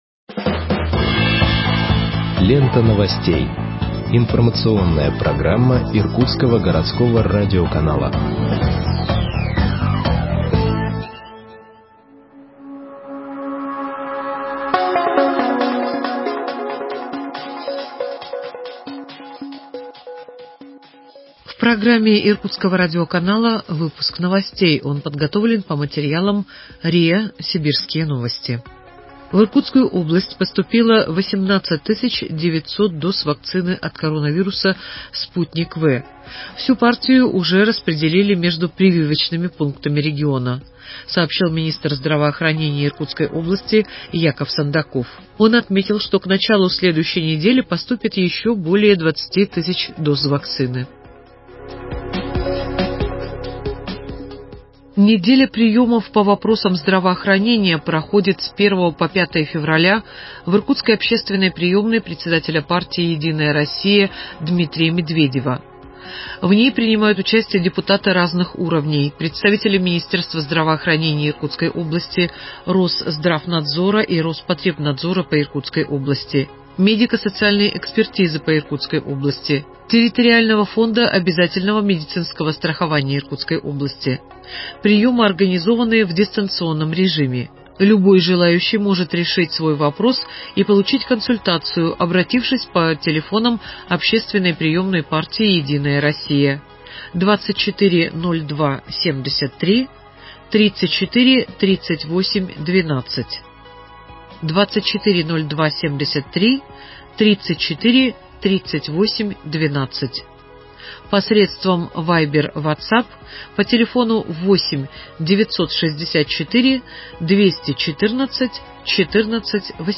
Выпуск новостей в подкастах газеты Иркутск от 05.02.2021 № 1